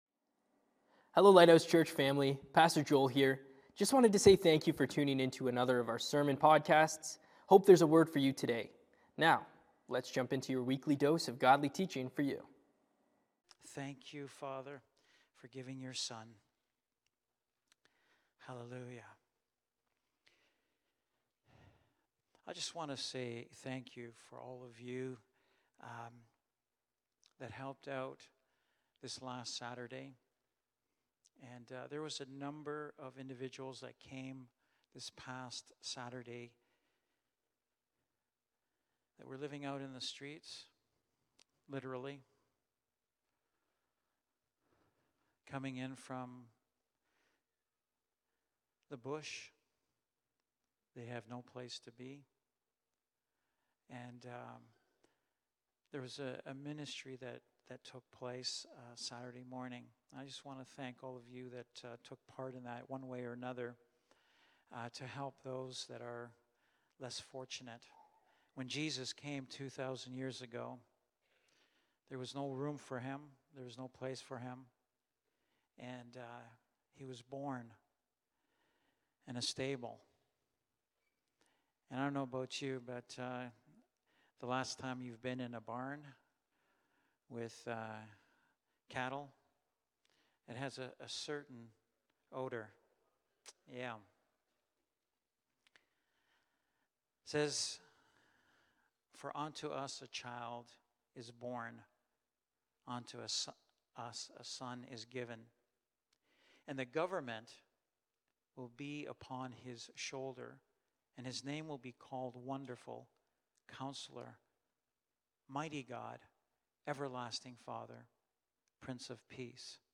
Christmas Eve Service
Lighthouse Niagara Sermons